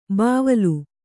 ♪ bāvalu